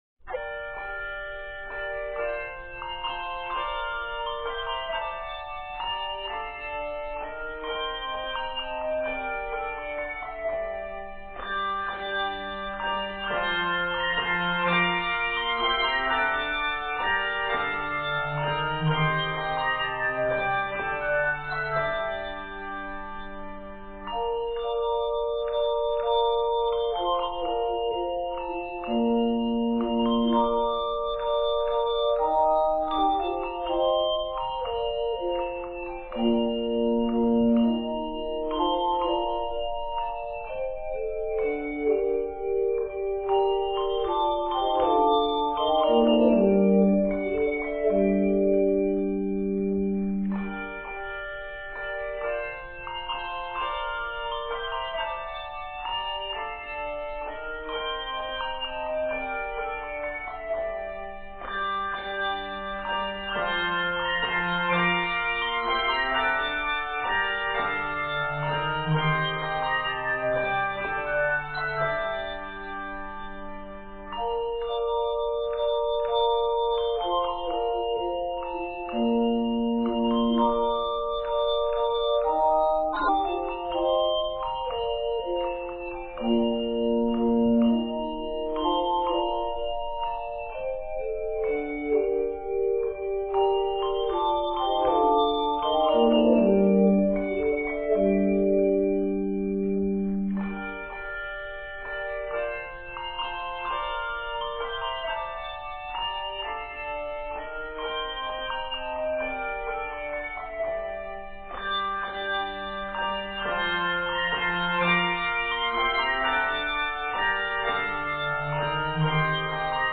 arranged for handbells and chimes